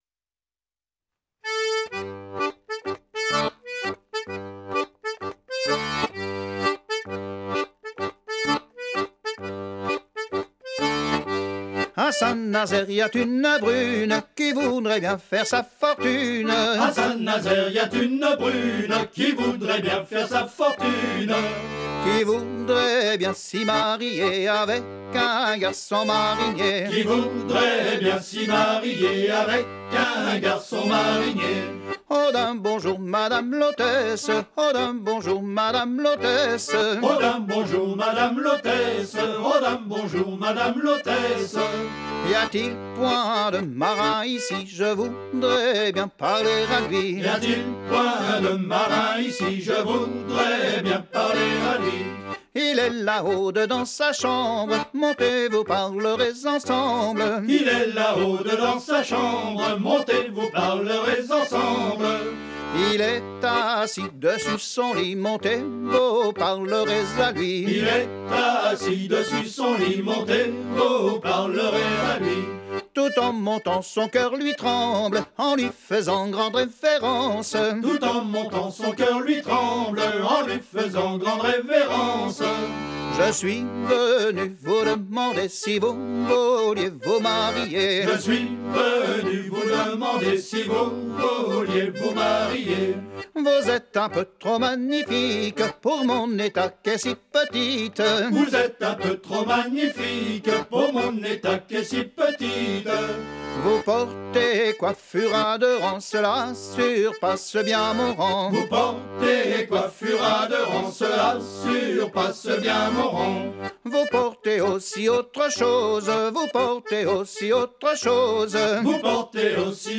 danse : ronde à la mode de l'Epine
Pièce musicale éditée